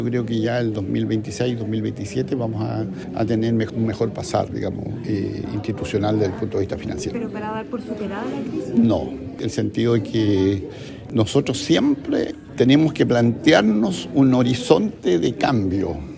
En conversación con los medios, dijo que la universidad se podría recuperar entre 2026 y 2027.